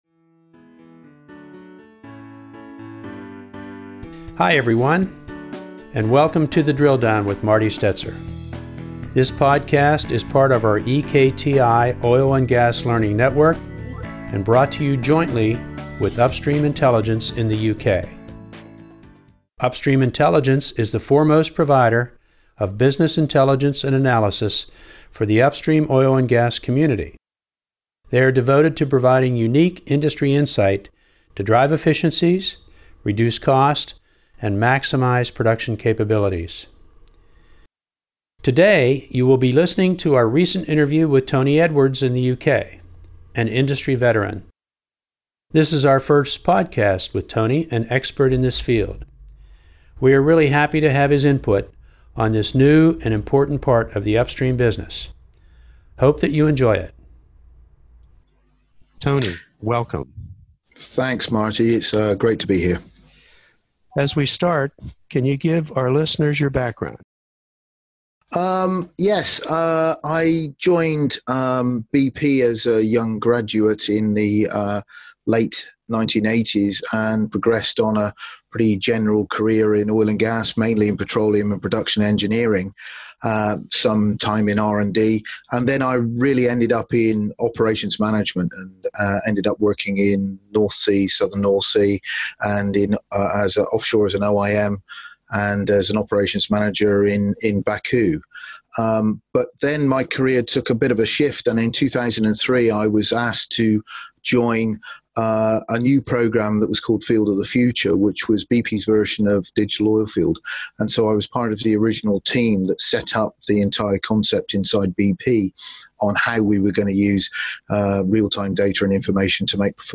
In this episode of Drill Down, we interview a true expert in the digital oilfield and IoT.